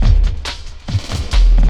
59 LOOP 03-L.wav